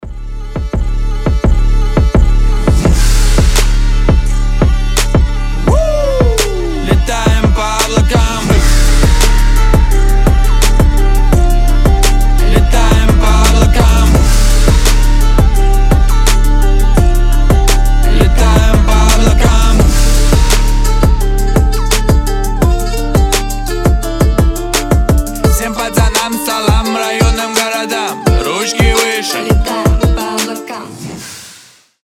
• Качество: 320, Stereo
басы
биты